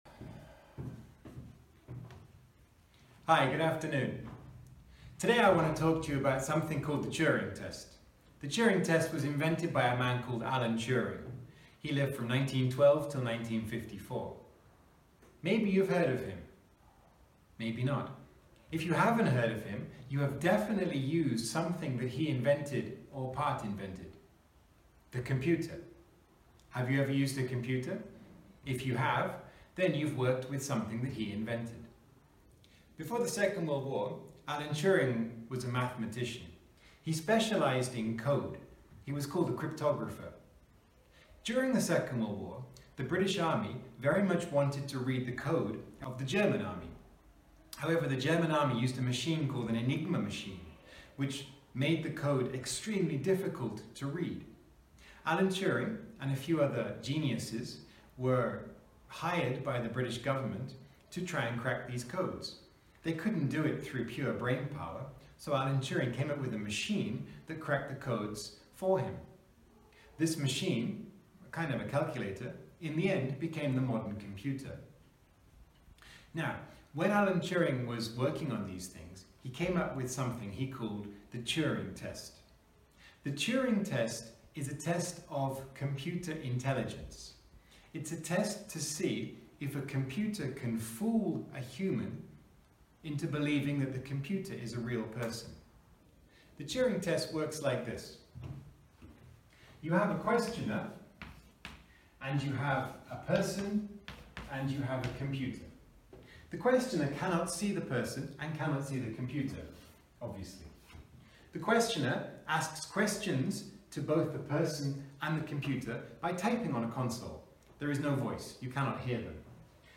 Posted in Long talk | Tagged , , , , | Comments Off on #4 The Turing Test